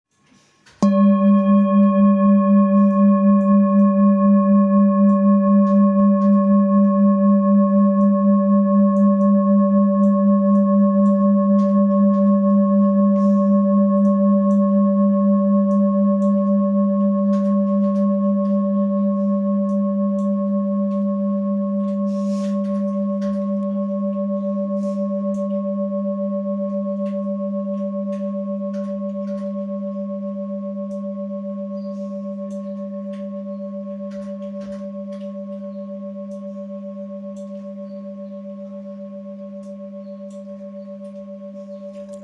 Handmade Singing Bowls-30417
Singing Bowl, Buddhist Hand Beaten, Plain, Antique Finishing, Select Accessories
Material Seven Bronze Metal